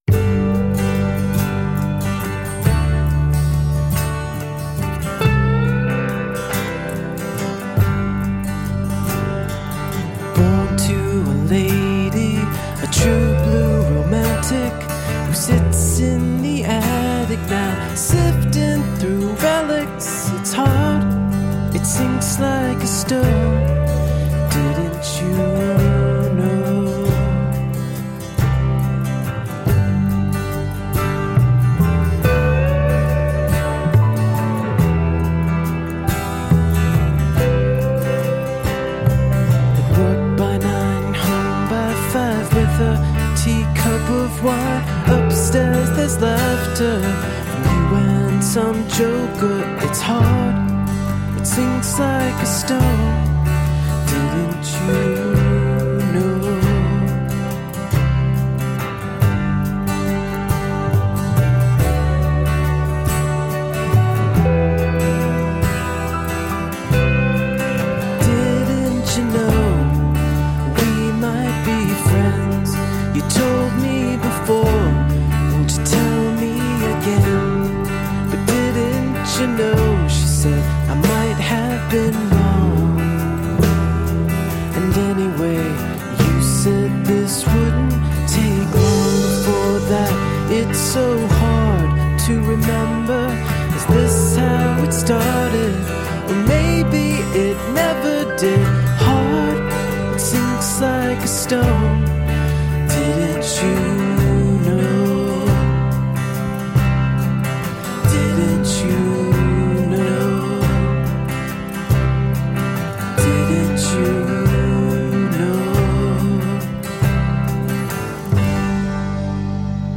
A catchy brand of alternative roots rock.
Tagged as: Alt Rock, Folk-Rock, Folk